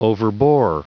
Prononciation du mot overbore en anglais (fichier audio)